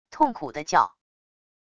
痛苦地叫wav音频